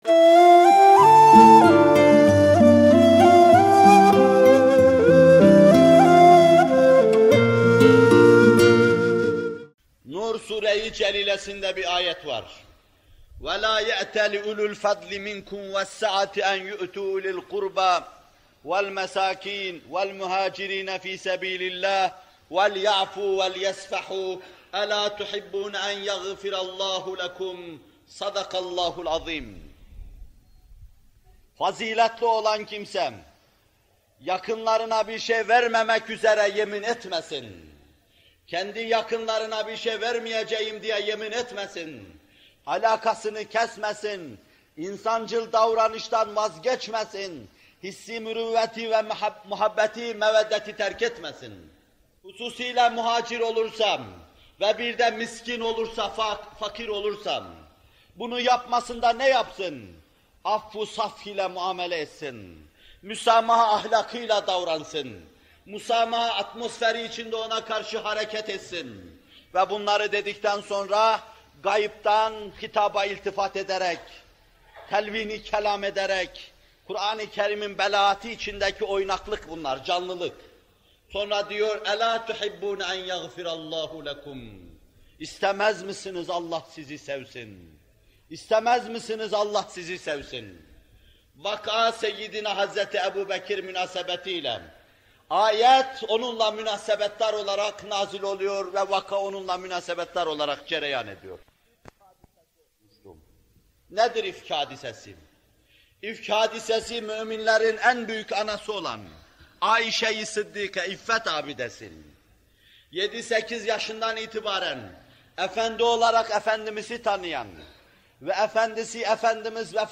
Muhterem Fethullah Gülen Hocaefendi bu videoda Nur Suresi 22. ayet-i kerimesinin tefsirini yapıyor: